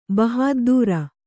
♪ bahāddūra